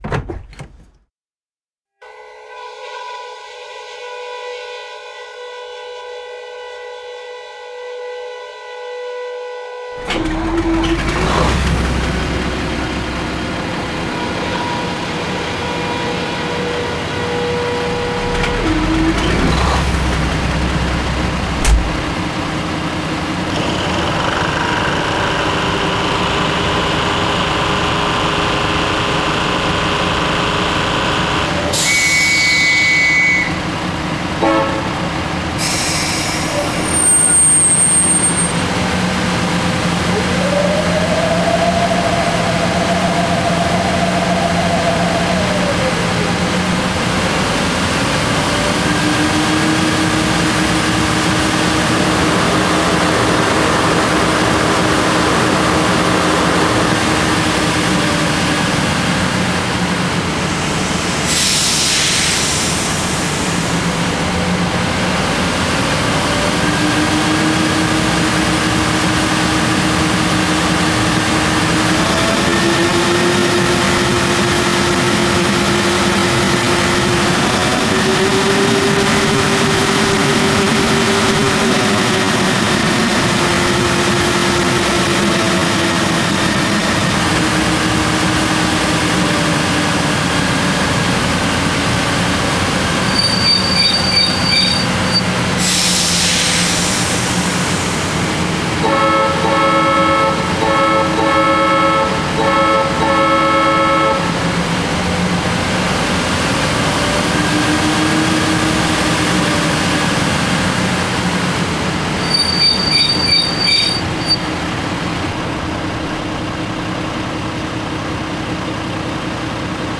Renfe Serie 340 (Renfe Serie 4000, Krauss-Maffei ML 4000 B’B’) diesel-
Renfe_340.mp3